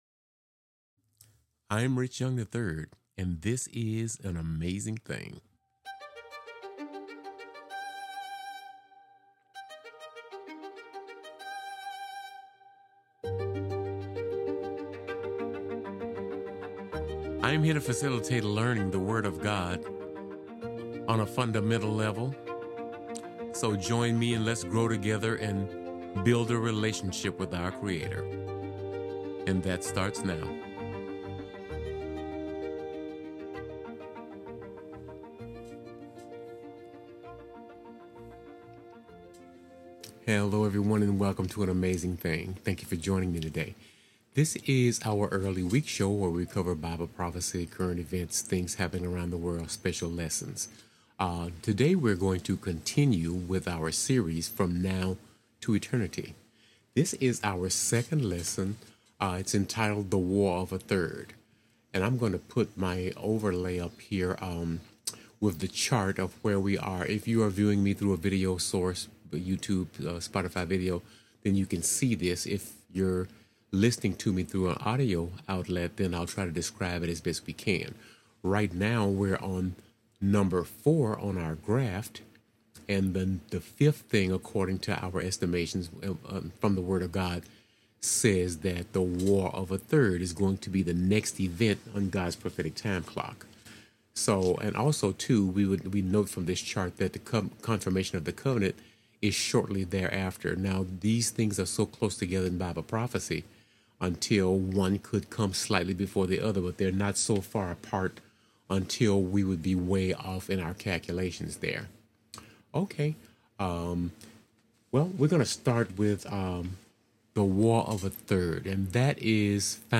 This is the early week show that is the second lesson in our series now to eternity?